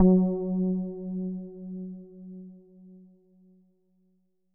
SPOOKY F#2.wav